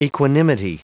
Prononciation du mot equanimity en anglais (fichier audio)
Prononciation du mot : equanimity